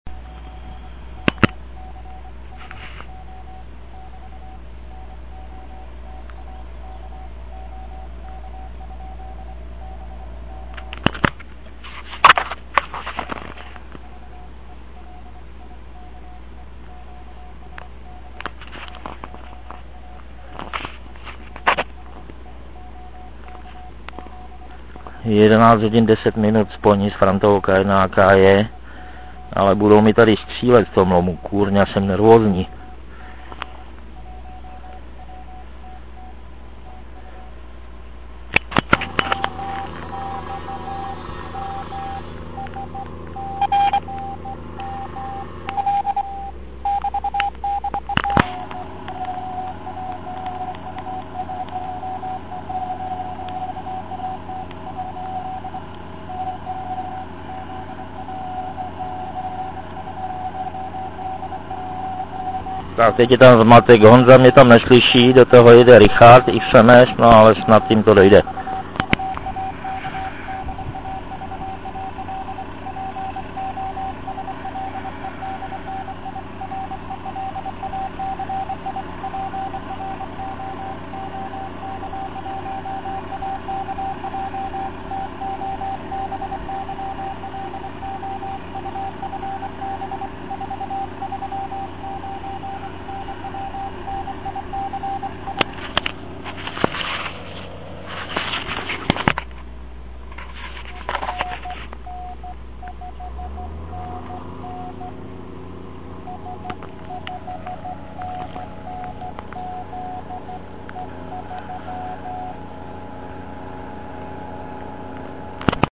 Nahrávky nejsou nejkvalitnější.
Nahrávku jsem musel dělat tak, že jsem "špuntové" sluchátko přikládal na mp3 nahravač.